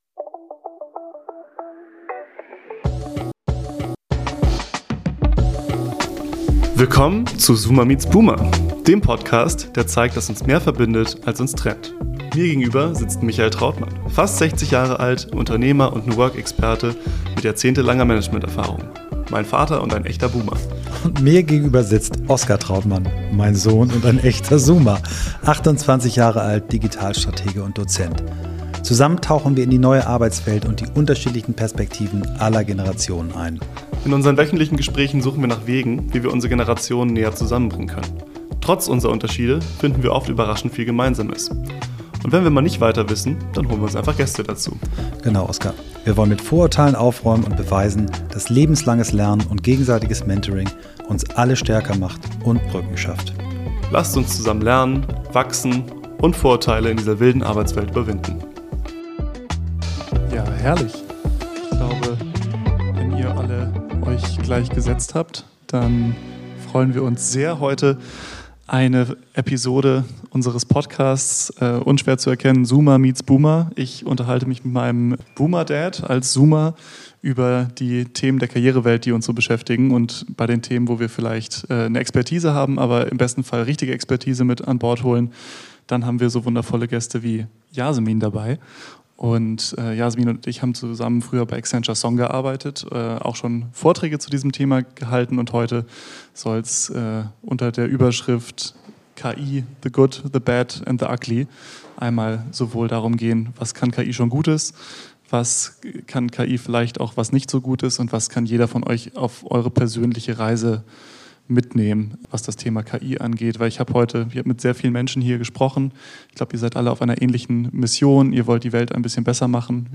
Live Podcast AI Impact Days ~ Zoomer Meets Boomer